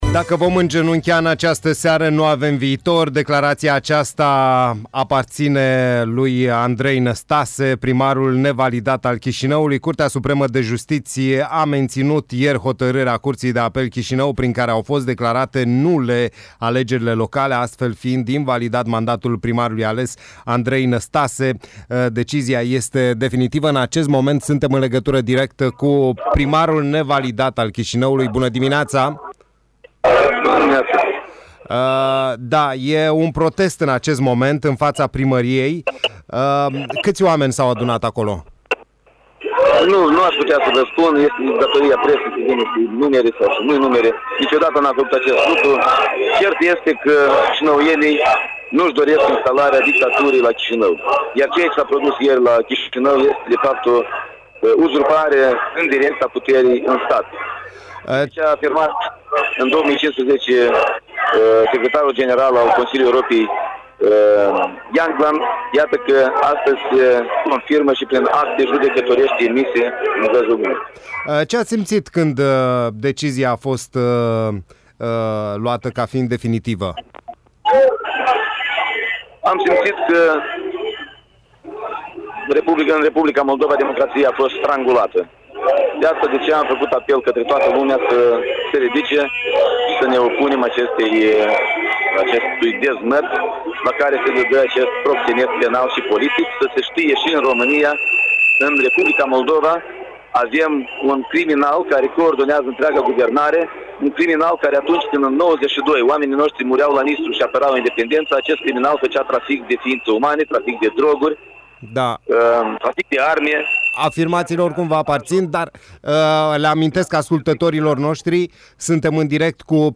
Andrei Năstase a acordat un interviu în dimineața zilei de marți, 26 iunie din mijlocul protestelor din fața primăriei din Chișinău.